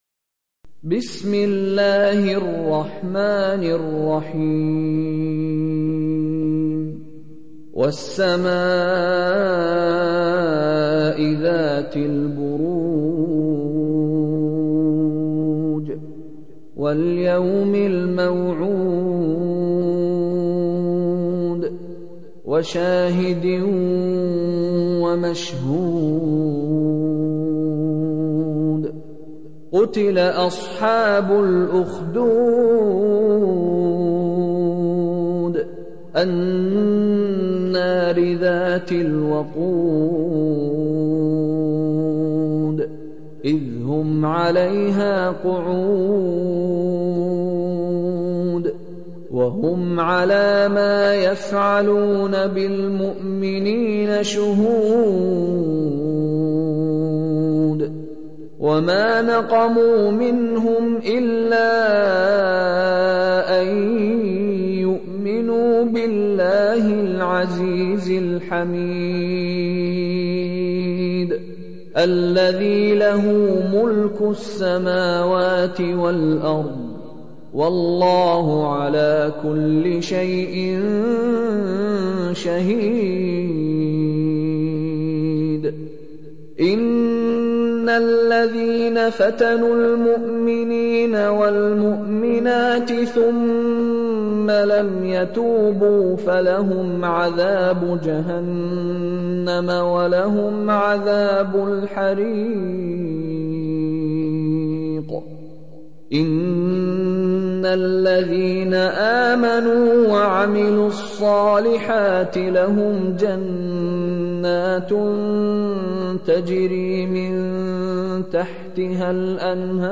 Чтение Корана > МИШАРИ РАШИД